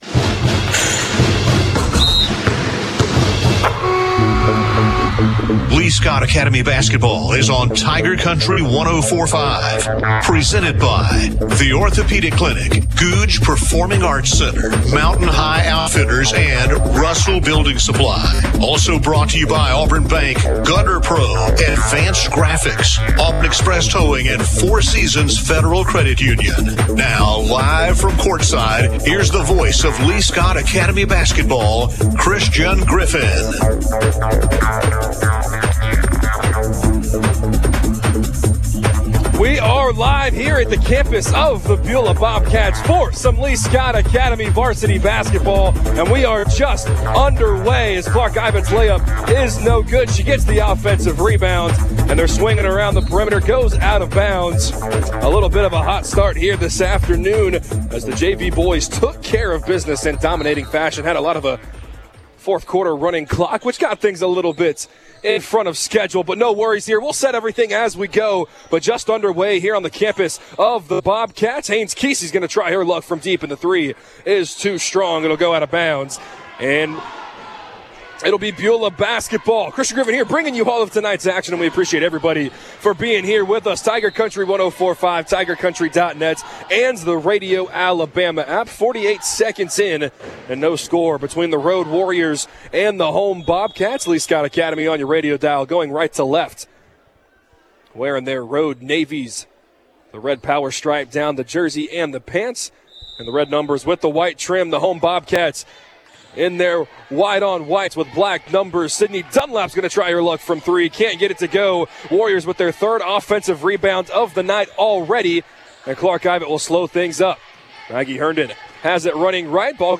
(Girls Basketball) Lee-Scott Academy vs. Beulah